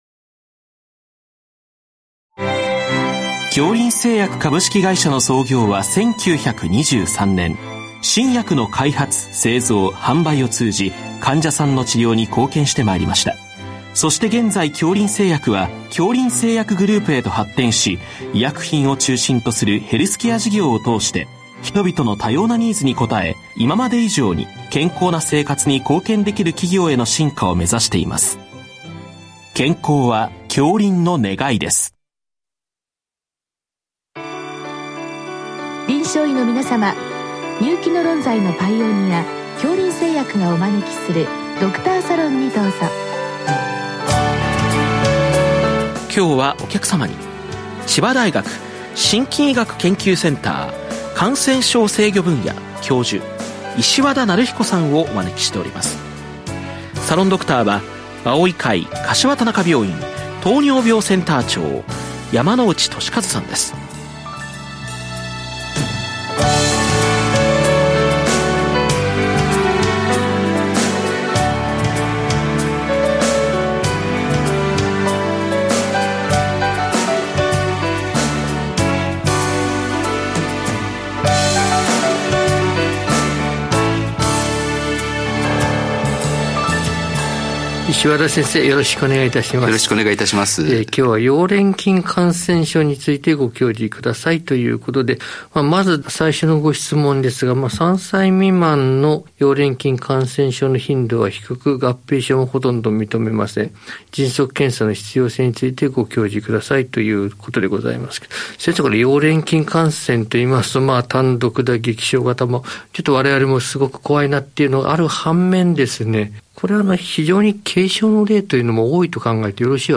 全国の臨床医の方々にむけた医学情報番組。臨床医の方々からよせられたご質問に、各分野の専門医の方々にご出演いただき、解説いただく番組です。